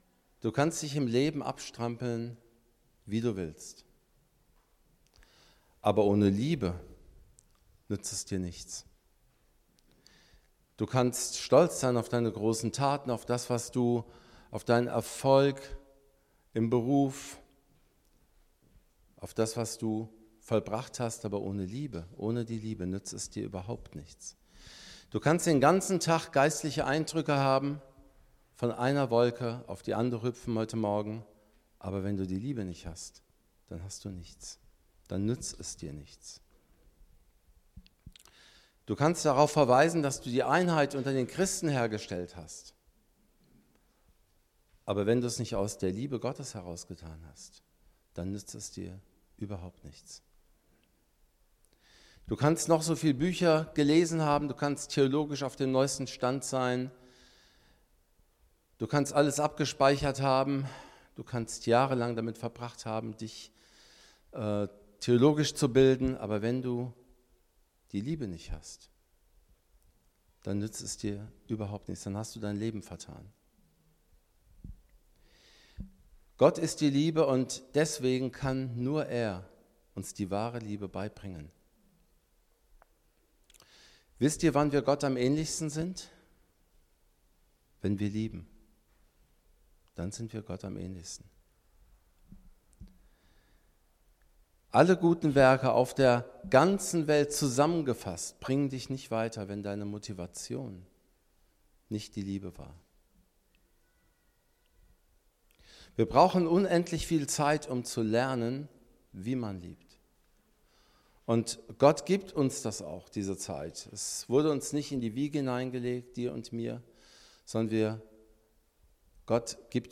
Korinther 13 Dienstart: Predigt Bible Text: 1.